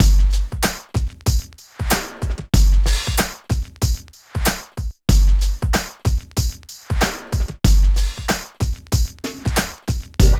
69 DRUM LP-L.wav